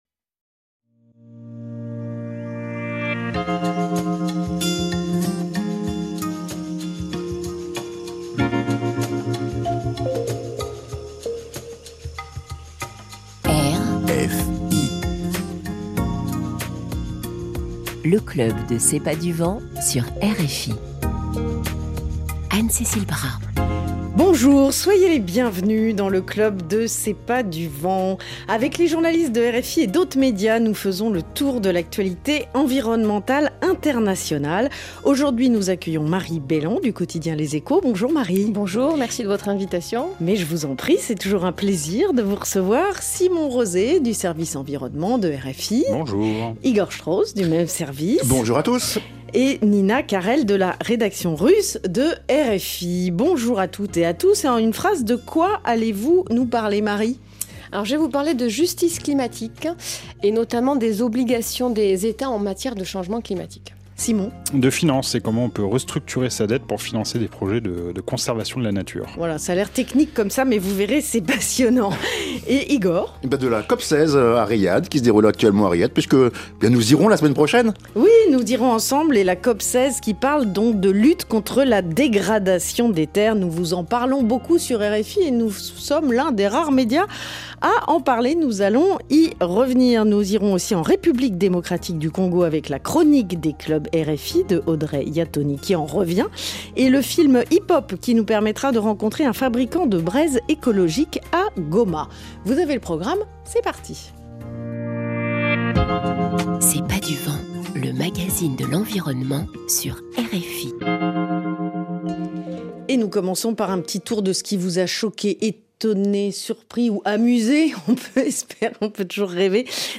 Des journalistes spécialistes de l'environnement, de RFI et d'ailleurs, reviennent sur les sujets environnementaux qui les ont marqués et partagent les coulisses de leur travail. L'occasion aussi de commenter les reportages produits par les vidéastes du réseau ePOP et les actions des Clubs RFI.